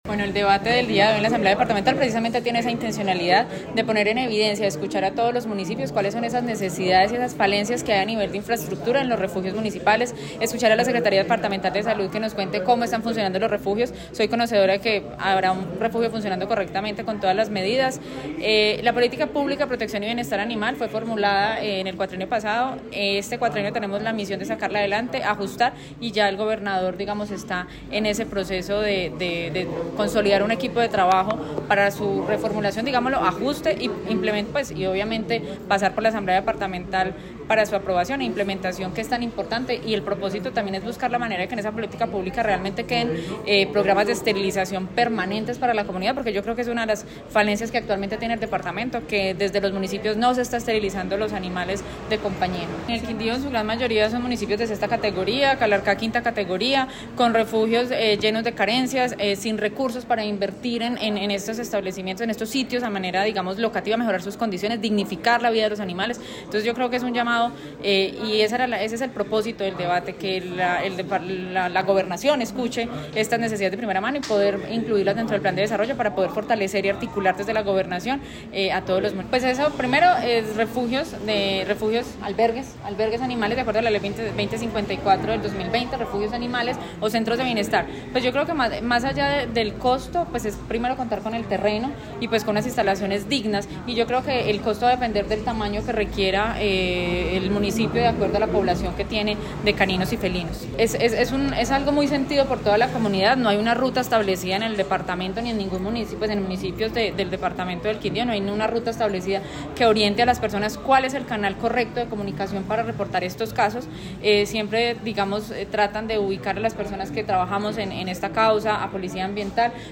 Audio: Diputada proponente, Beatriz Elena Aristizábal
Beatriz_Elena_Aristiza_bal_diputada_proponente_AUDIO.mp3